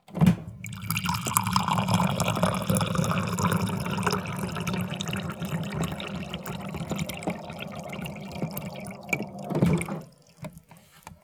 watercool.wav